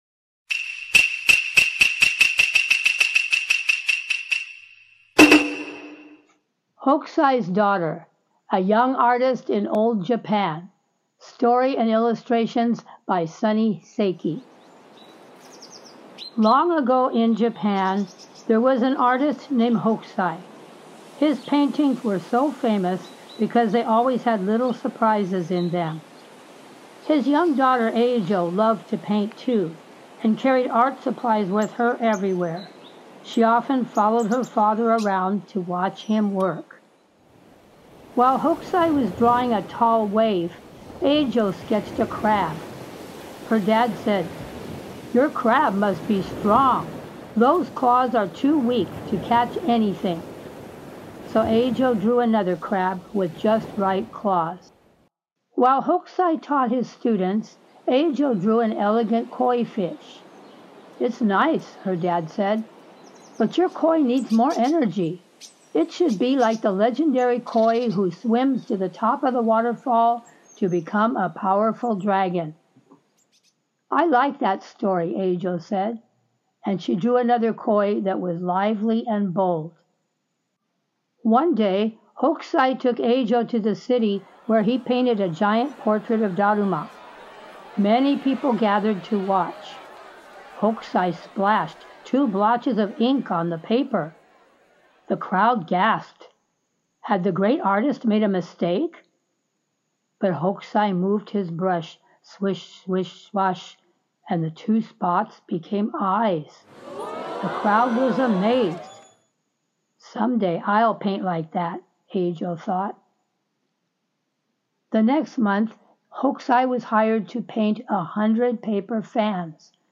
Hokusai's Daughter told in English